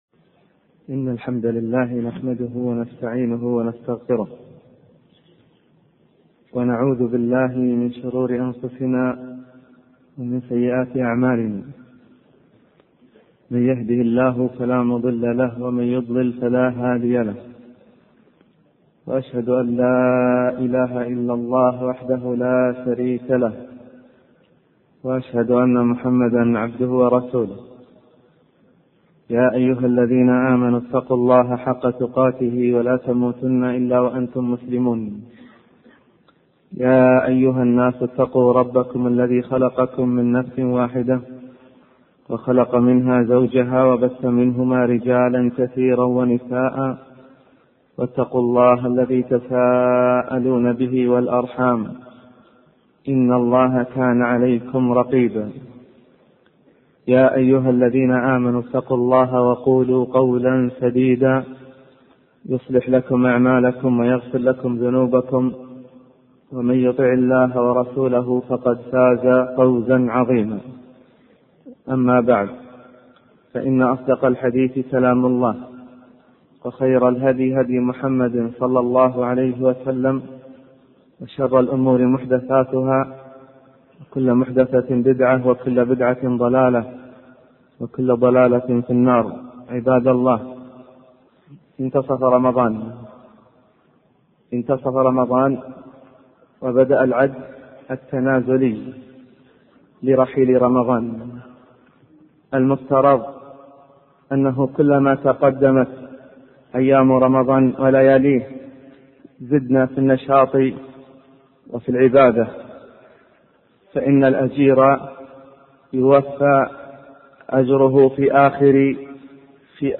الخاتمة الدعوة إلى محاسبة النفس على التقصير في العبادة، خاصة في الصلاة، والتذكير بأن العابدين والزهاد هم قلة نادرة، لكن بهم تنتصر الأمة. المؤمن مدعو لمحاسبة نفسه، والاجتهاد في العبادة، فبصلاح العابدين تصلح الأمة. المحاضرات الصوتية